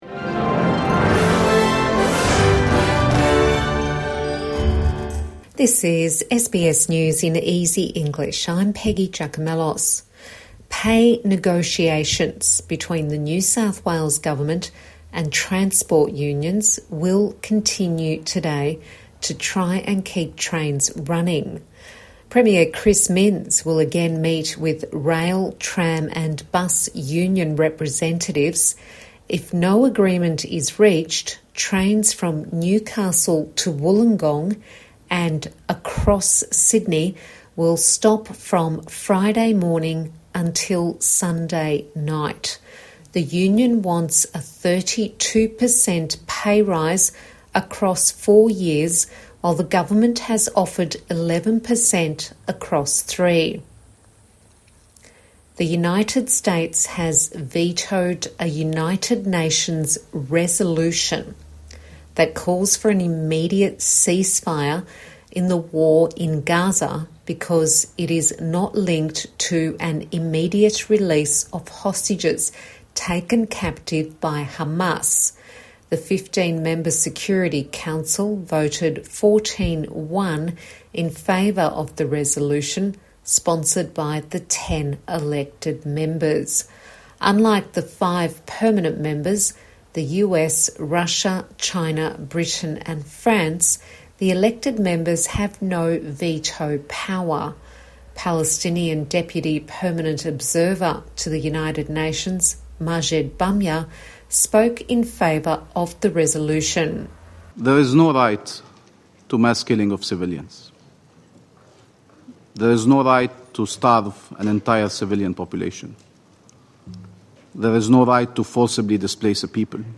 A five minute news bulletin for English language learners